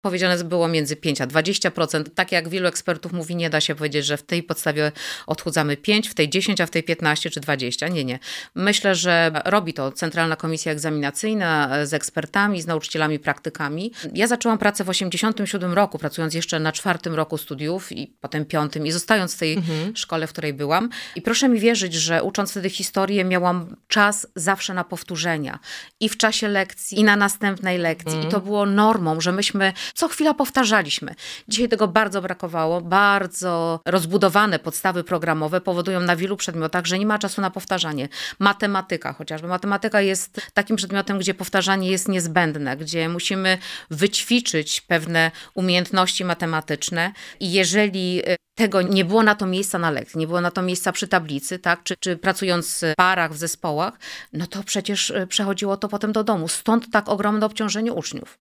-Obecna postawa programowa to ogromne obciążenie dla uczniów, mówi Ewa Skrzywanek – pełniąca obowiązki dolnośląskiego kuratora oświaty.